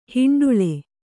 ♪ hiṇḍuḷe